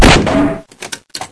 glauncher2.ogg